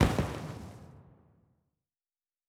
Firework (2).wav